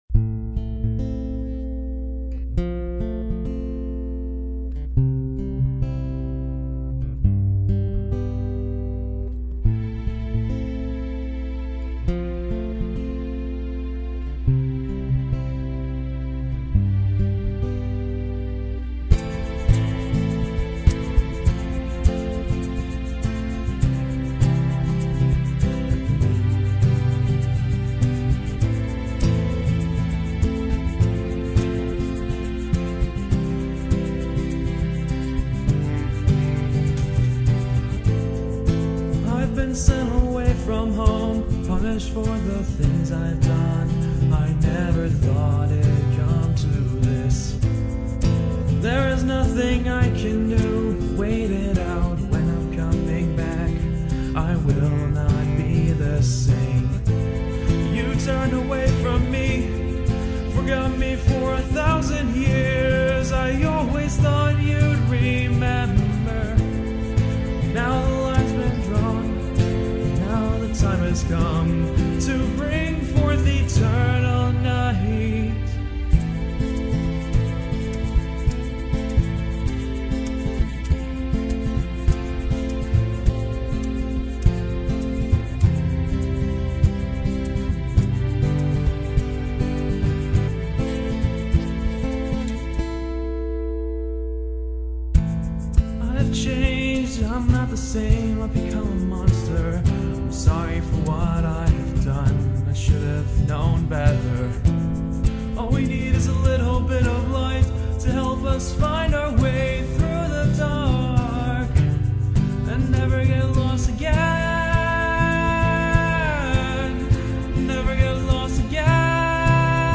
Chords: Am Fmaj7 C G